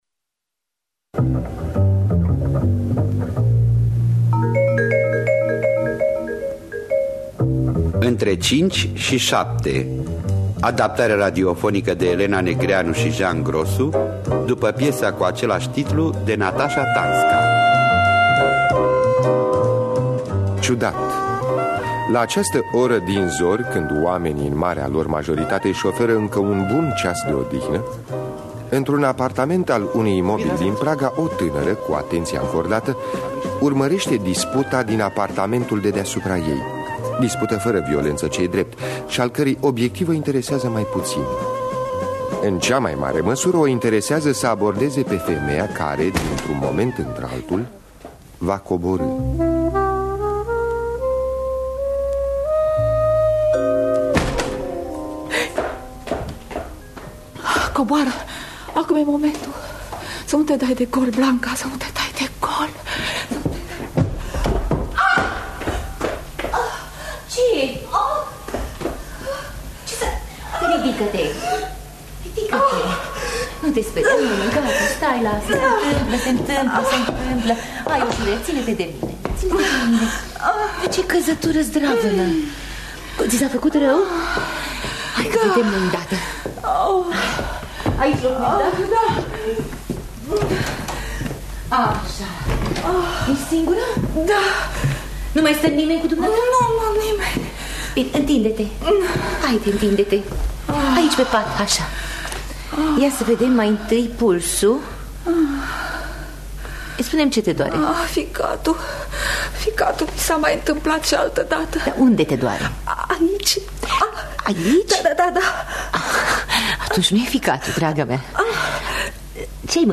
Adaptarea radiofonică
În distribuţie: Gina Patrichi